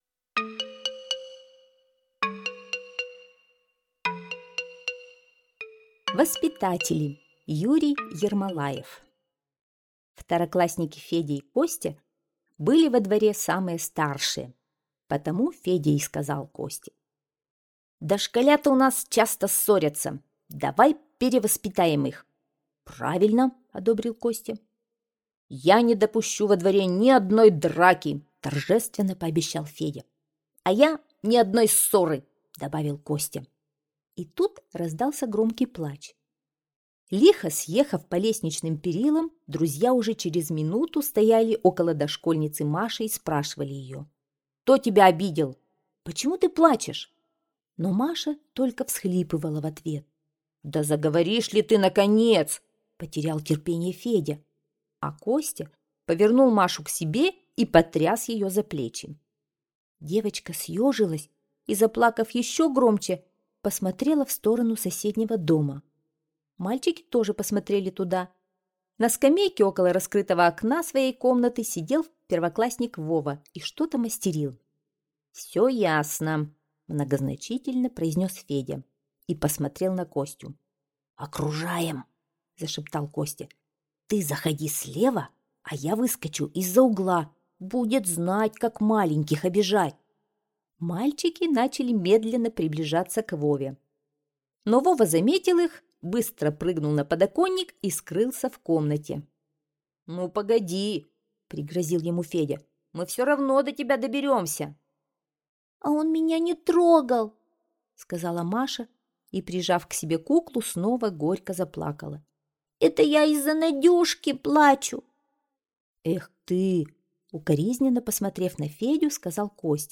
Воспитатели - аудио рассказ Ермолаева - слушать